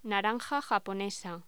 Locución: Naranja japonesa
voz